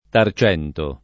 Tarcento [ tar ©$ nto ]